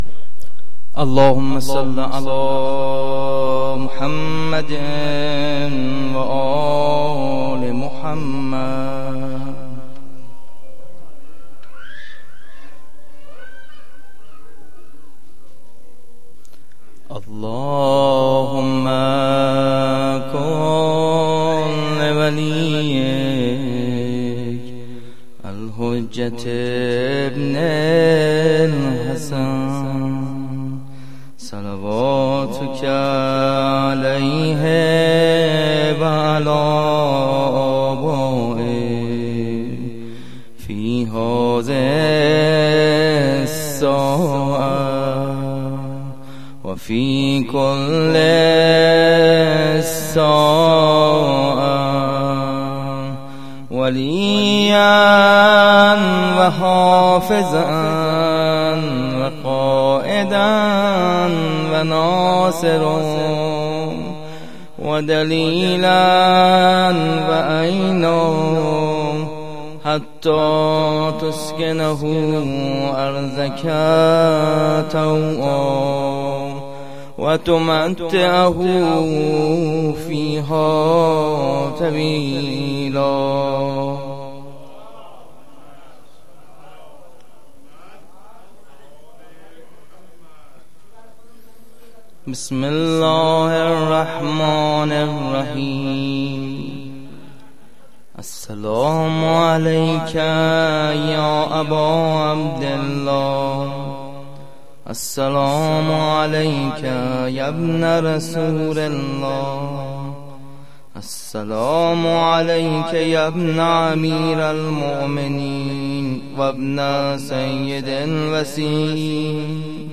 خیمه گاه - هیئت مهدیه احمد آباد - زیارت عاشورا-شب سیزدهم-محرم97-مهدیه احمدآباد